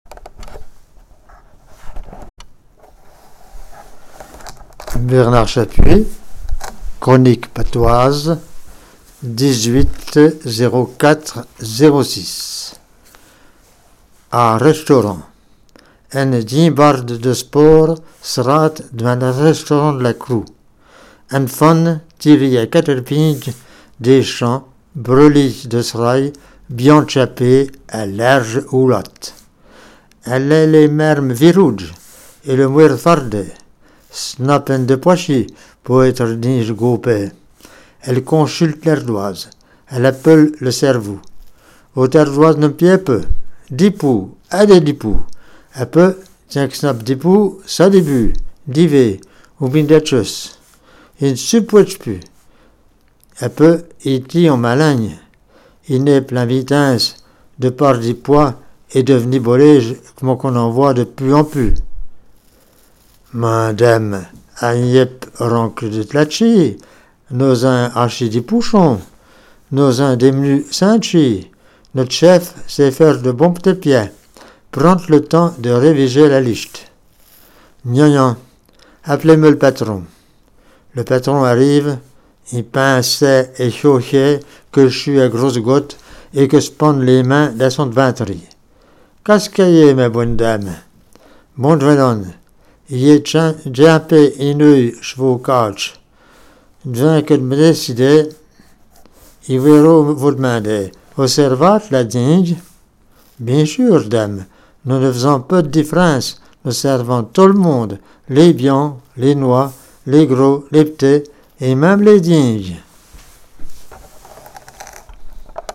Patois Jurassien